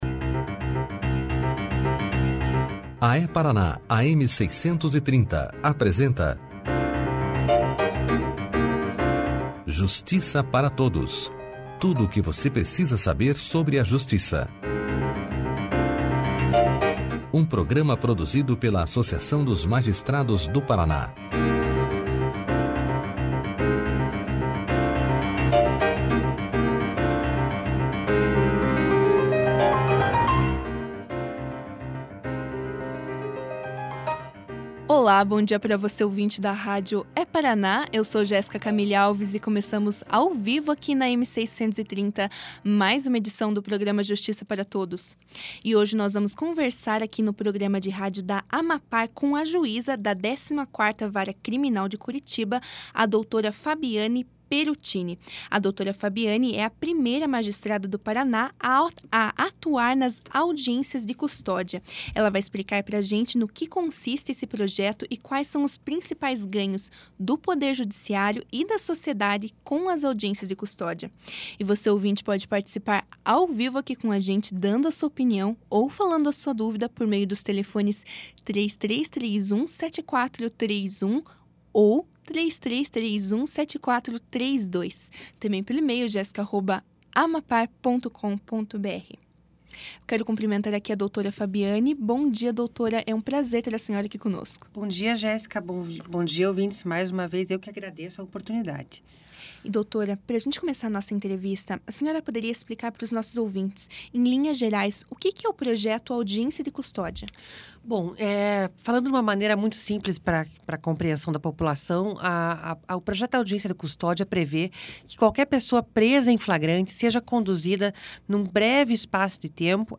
A magistrada explicou em que consiste o projeto e quais são os principais ganhos do poder judiciário e da sociedade com as audiências de custódia. Além disso, a experiência da juíza no projeto, os resultados até o momento e quais foram os principais crimes pelos quais as pessoas foram detidas e levadas às audiências foram alguns dos assuntos abordados durante a entrevista.
Clique aqui e ouça a entrevista da juíza Fabiane Pieruccini sobre o Projeto Audiência de Custódia na íntegra.